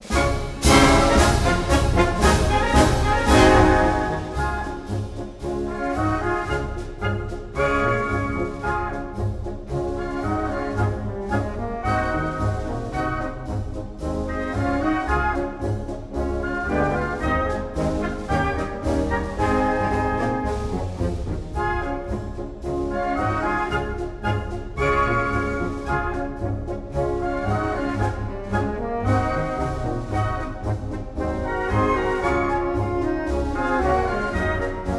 Classical Marching Brass Woodwinds
Жанр: Классика